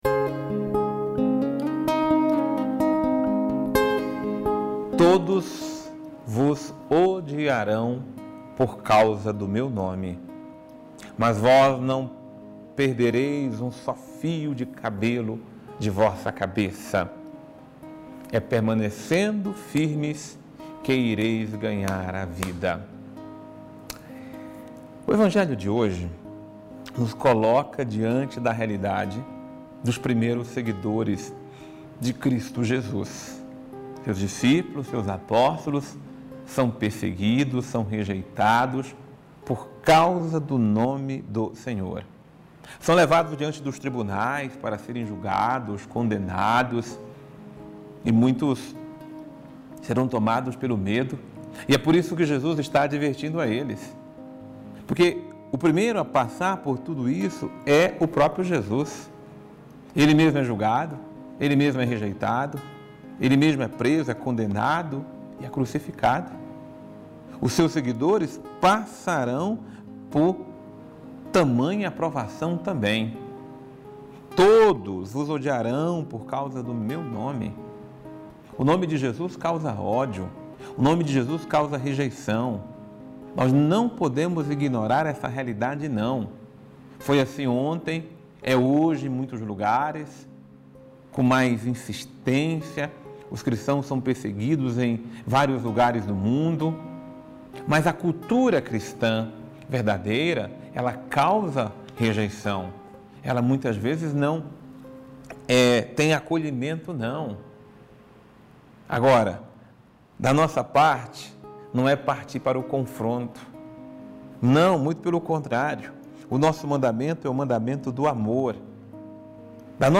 Homilia diária | Permaneçamos com os olhos fixos em Jesus